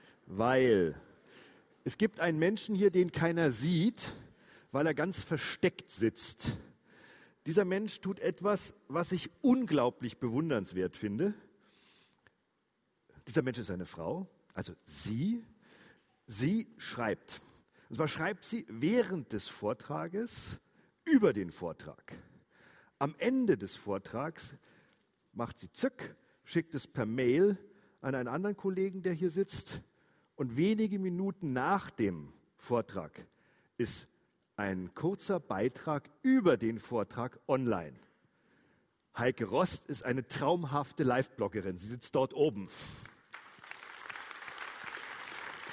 auf der Münchner Design-Konferenz QVED2015
Das komplette Statement